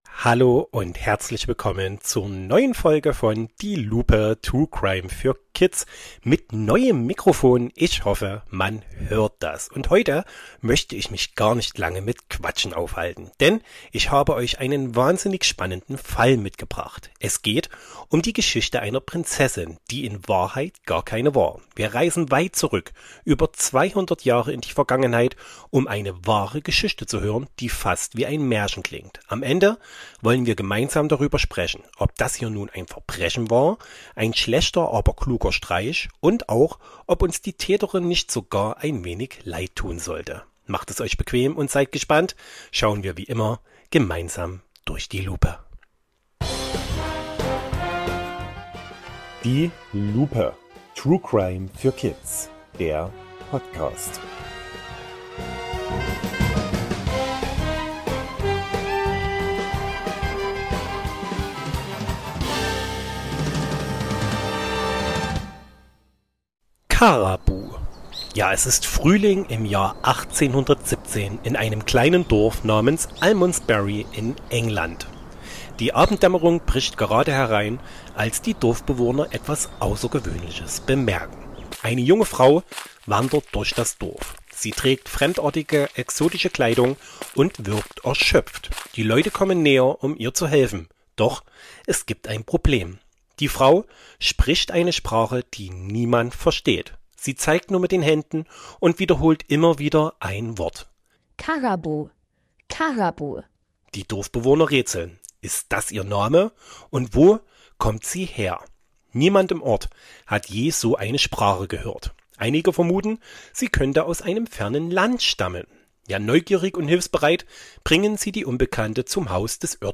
Ein echter Betrugsfall Eine Rolle, die fast perfekt gespielt war Spannend, ruhig und kindgerecht erzählt Mehr